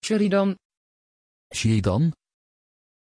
Pronunciation of Sheridan
pronunciation-sheridan-nl.mp3